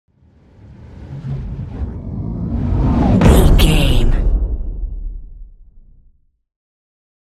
Cinematic whoosh to hit deep
Sound Effects
Atonal
dark
intense
tension
woosh to hit